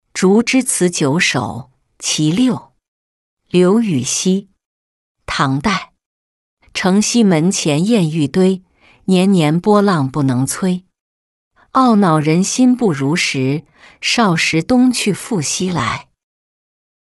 竹枝词九首·其六-音频朗读